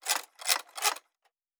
Metal Tools 07.wav